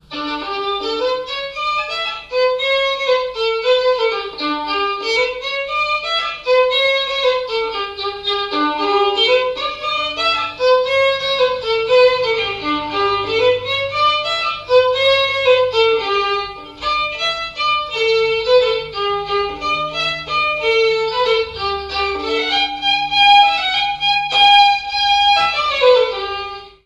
Chants brefs - A danser
danse : polka
répertoire de bals et de noces
Pièce musicale inédite